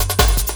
06LOOP05SD-R.wav